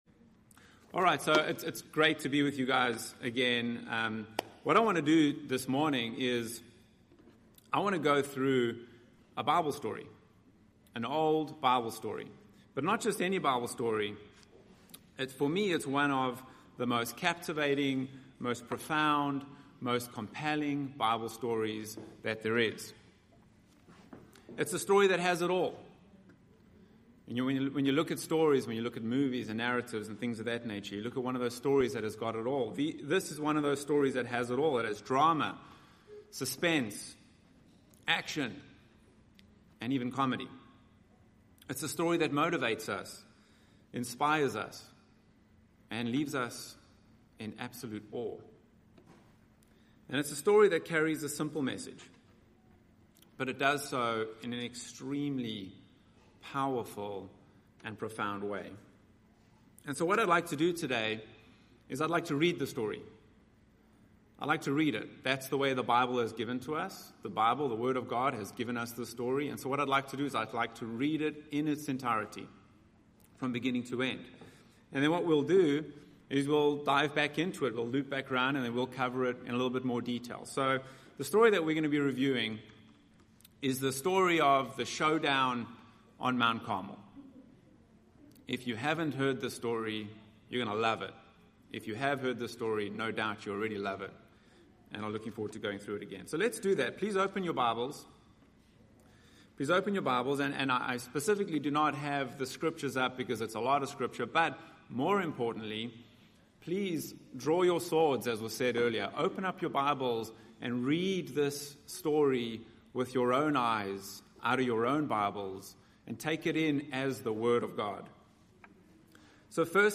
This sermon was given at the Montego Bay, Jamaica 2020 Feast site.